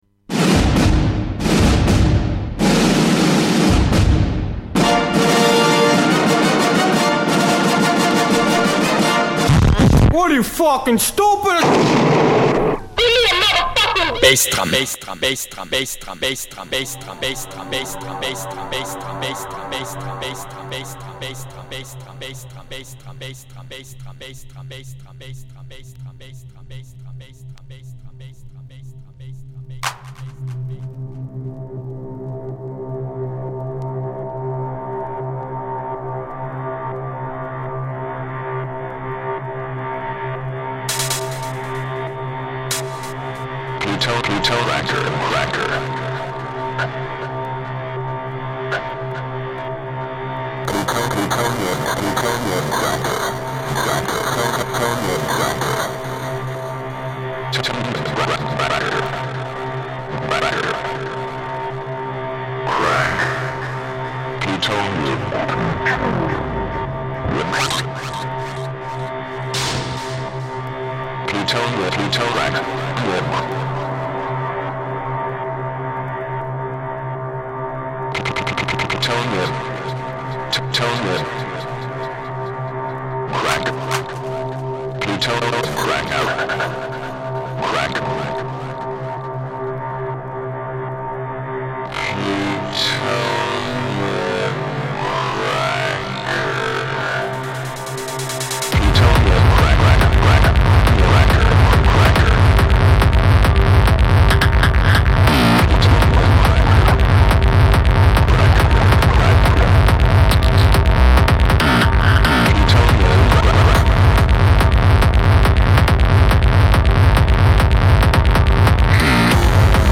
Capítulo correspondiente al mes de Enero de 2008 en el que damos un repaso al año que acabamos de dejar, recordando algunos de los hits que se han editado durante ese año. Además, comentamos anécdotas, fiestas y noticias de la Hardcore Scene y terminamos con una sesión inédita.